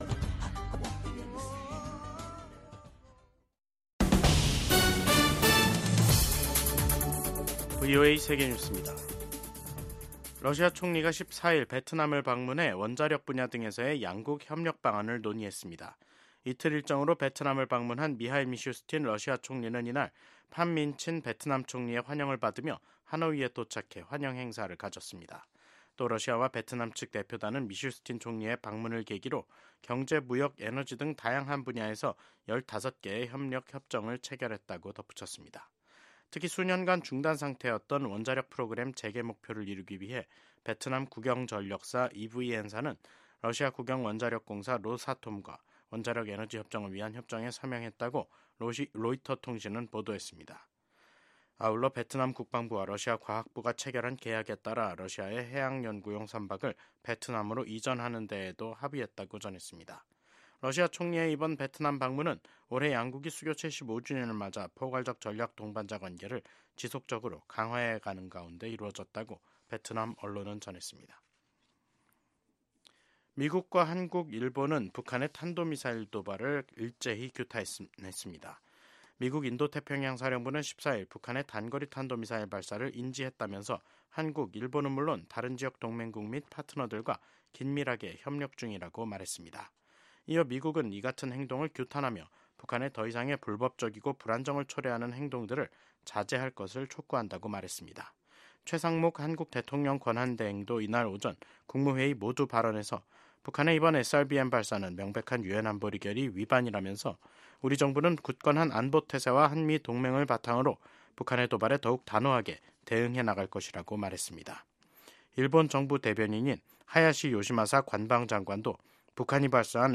VOA 한국어 간판 뉴스 프로그램 '뉴스 투데이', 2025년 1월 14일 3부 방송입니다. 북한이 8일만에 또 다시 탄도 미사일을 동해상으로 발사했습니다. 미국 국방부는 러시아 파병 북한군이 비교적 잘 훈련된 유능한 보병 병력으로, 우크라이나군에 위협이 되고 있다고 밝혔습니다. 미국의 한 전문가는 새로 들어설 미국의 도널드 트럼프 행정부가 혼란 상태에 빠진 한국 정부와 협력하는 것은 어려울 것으로 전망했습니다.